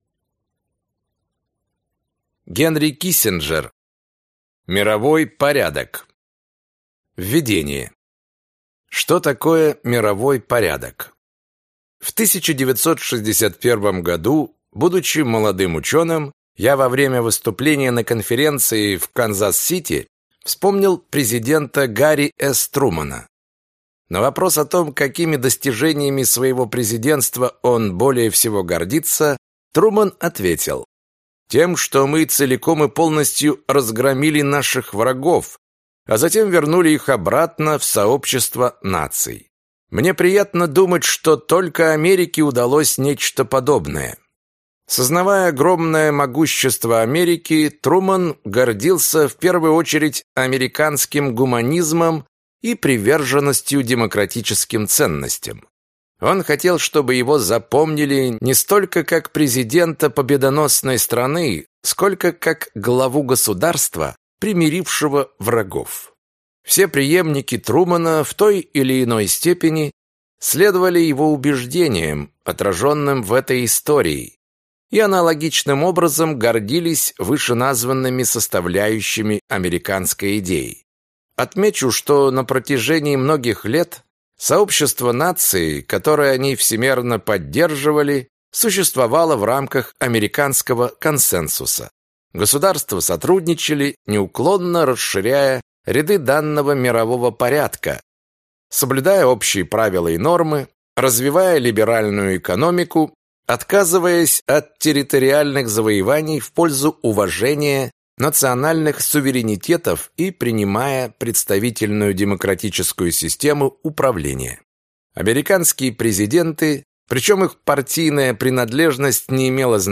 Аудиокнига Мировой порядок | Библиотека аудиокниг